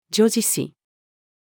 叙事詩-female.mp3